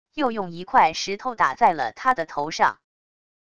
又用一块石头打在了他的头上wav音频生成系统WAV Audio Player